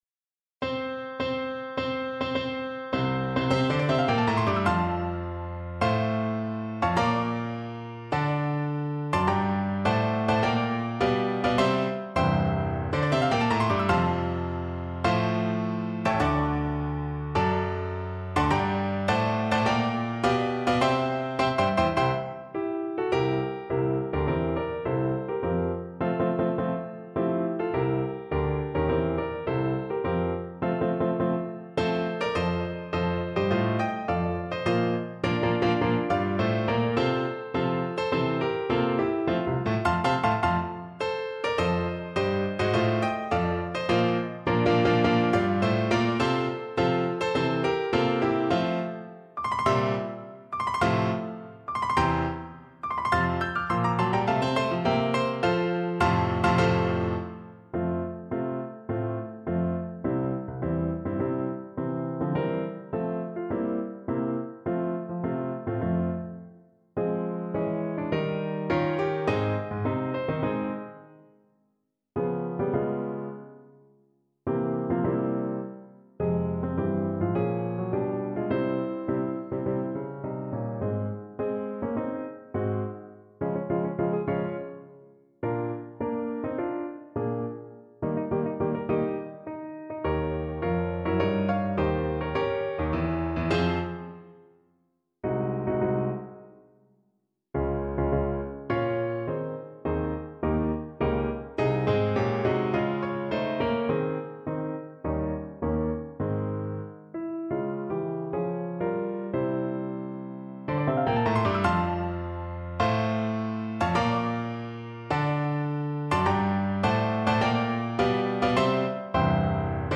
4/4 (View more 4/4 Music)
C5-G6
Marziale-Energico =104
Peruvian